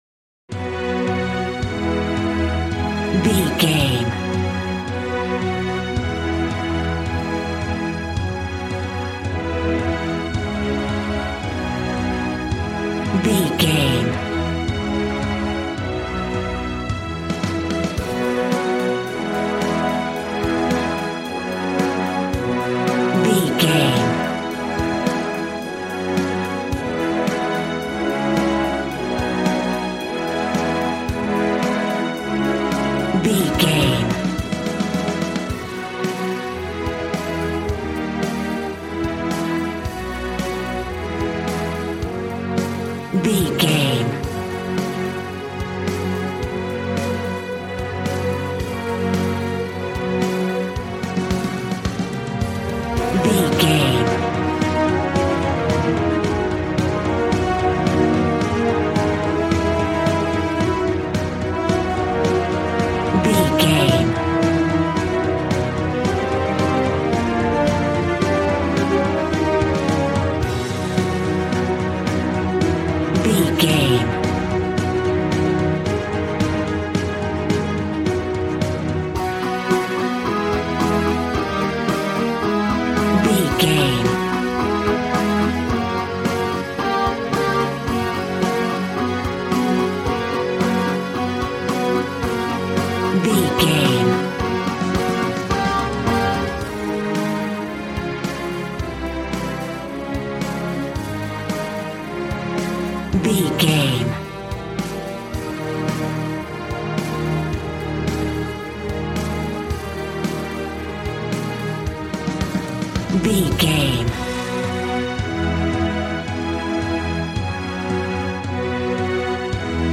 Ionian/Major
dramatic
epic
strings
violin
brass